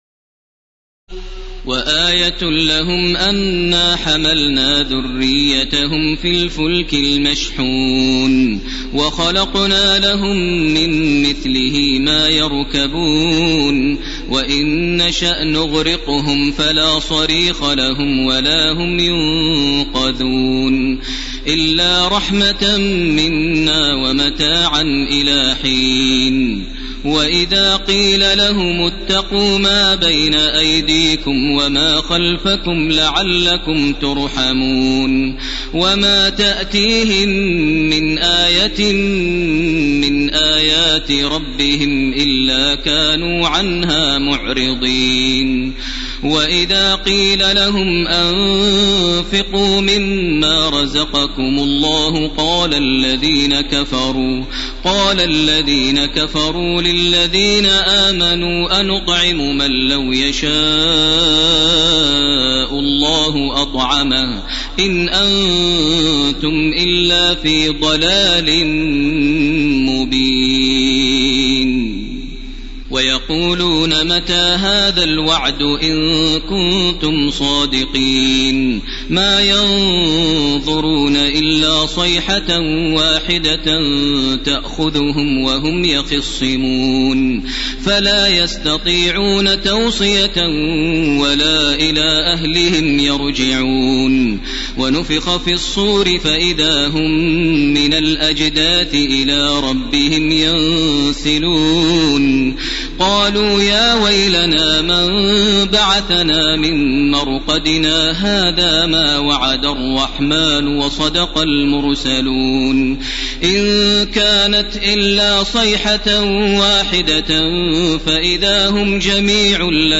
تراويح ليلة 22 رمضان 1431هـ من سور يس (41-83) و الصافات كاملة Taraweeh 22 st night Ramadan 1431H from Surah Yaseen to As-Saaffaat > تراويح الحرم المكي عام 1431 🕋 > التراويح - تلاوات الحرمين